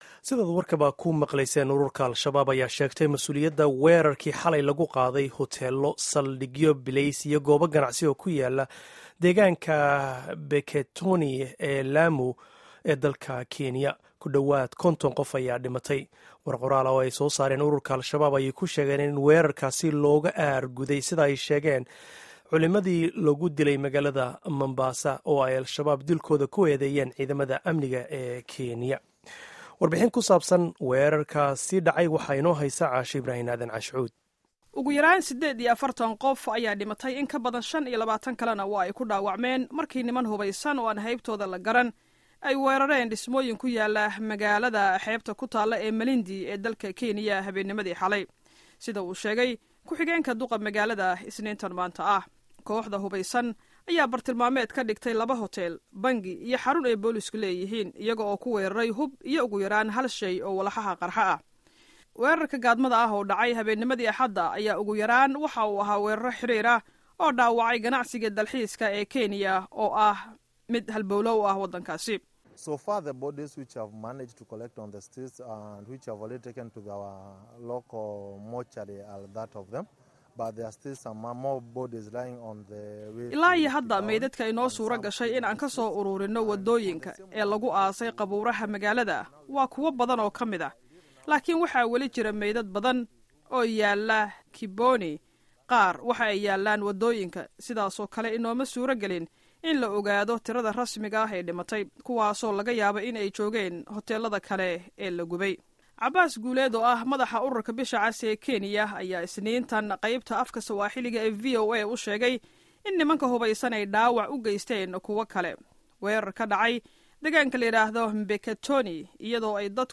Dhageyso warbixin iyo wareysi ku saabsan weerarkaasi